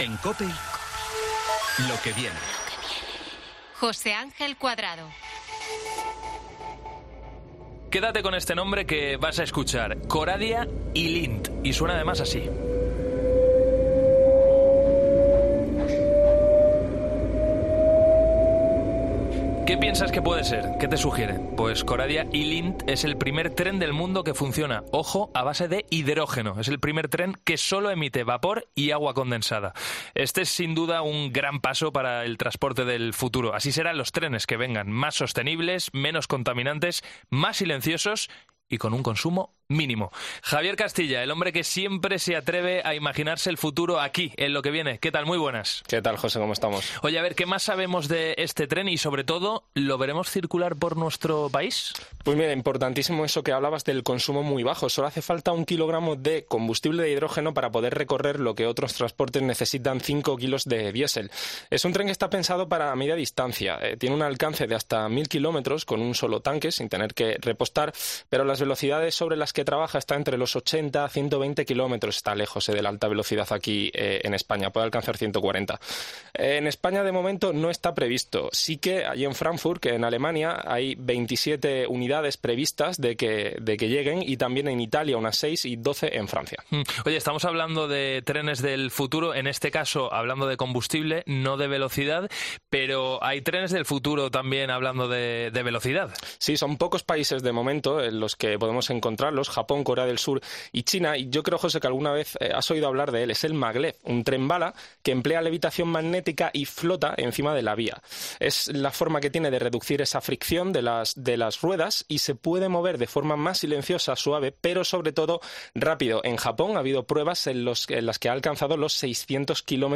Un experto habla del medio de transporte que nos permitirá viajar hasta París en poco más de una hora